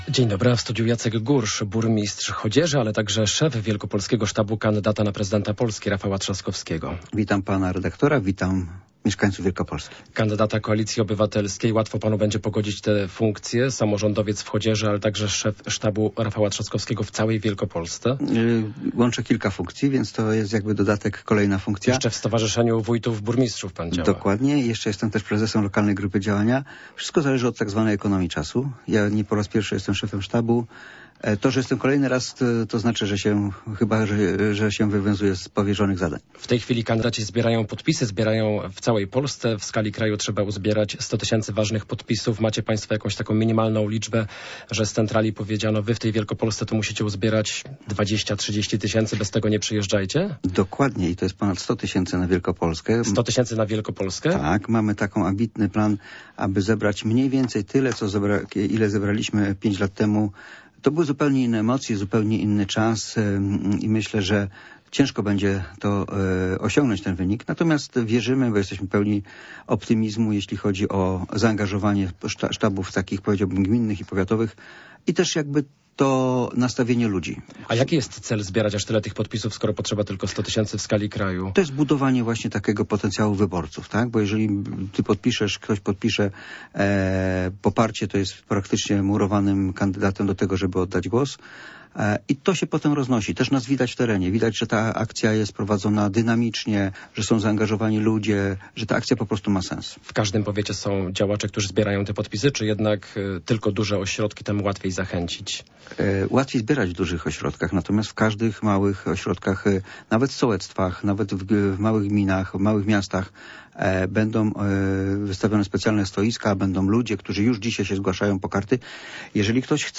Popołudniowa rozmowa Radia Poznań - Jacek Gursz
Gościem Popołudniowej Rozmowy Radia Poznań jest szef wielkopolskiego sztabu Rafała Trzaskowskiego, burmistrz Chodzieży Jacek Gursz.